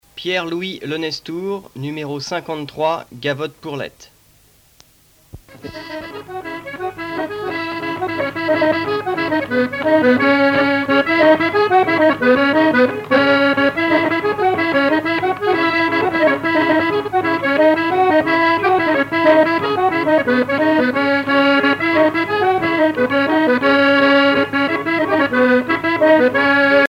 Gavotte pourlet
danse : gavotte bretonne
Pièce musicale éditée